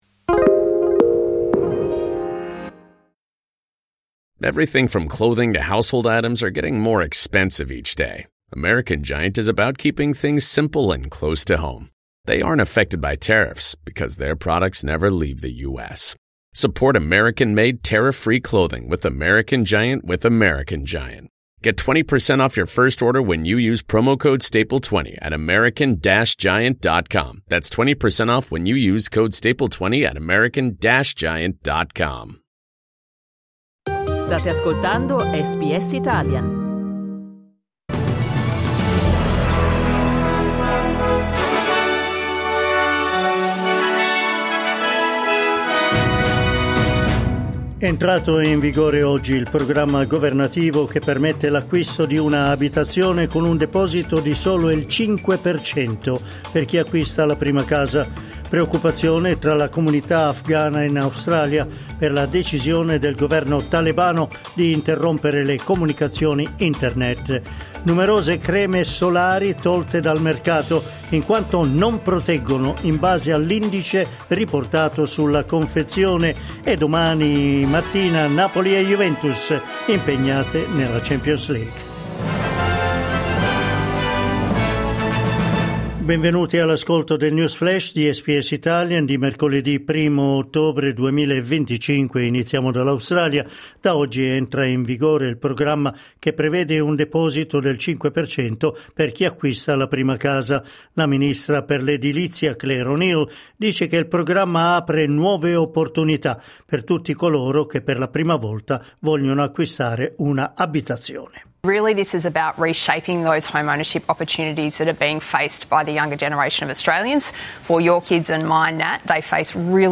News flash mercoledì 1 ottobre 2025